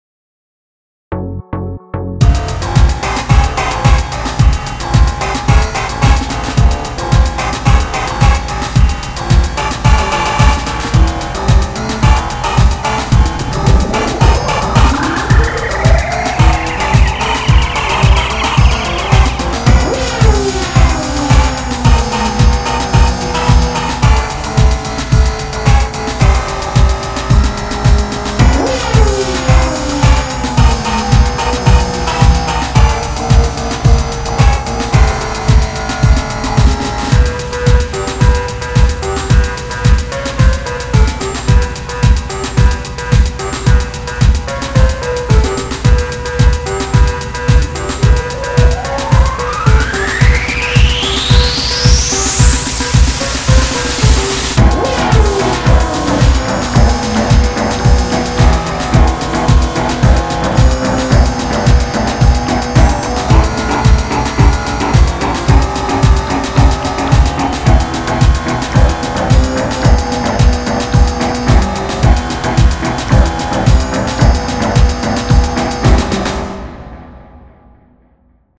BGM
EDMショート